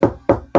Sound Effects
Knocking On Wall